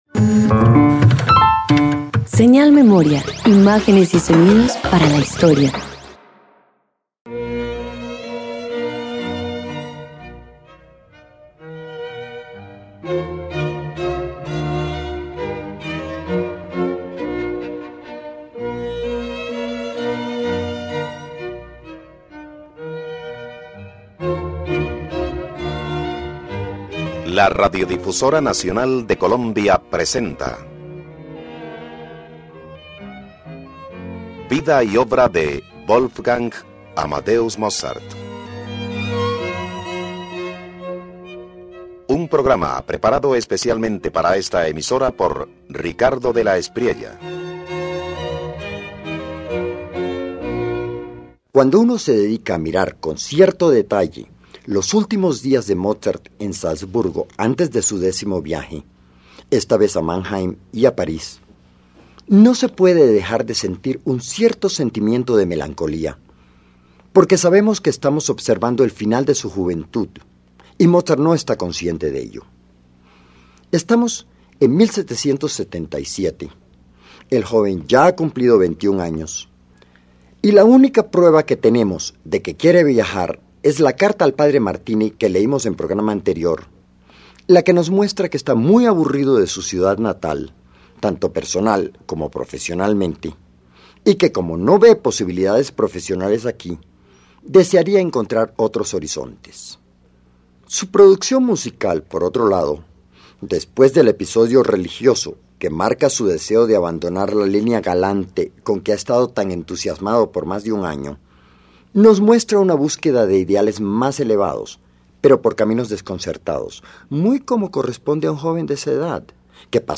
Antes de partir a Mannheim y París, Mozart compone el Concierto para oboe en do mayor, K314, una obra luminosa escrita para su colega Ferlendis. Su tono alegre y operático anticipa la madurez musical que alcanzará durante su viaje.